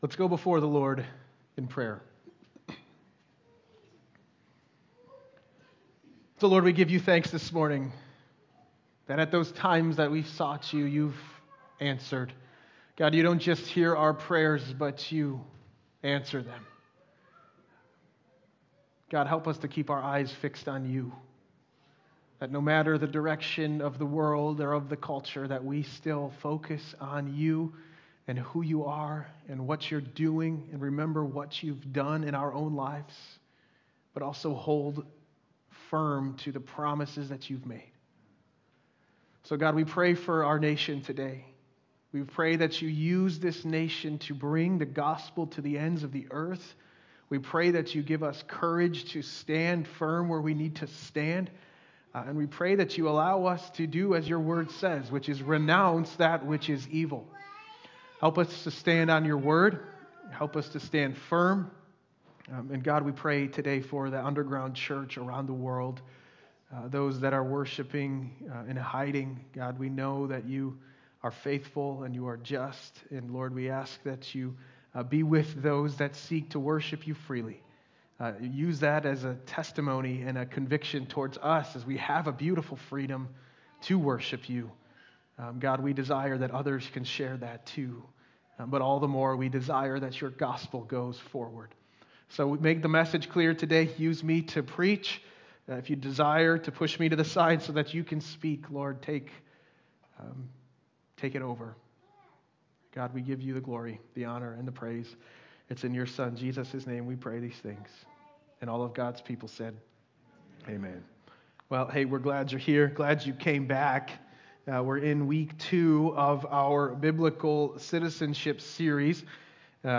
Sermons Archive - Hope Community Church Of Lowell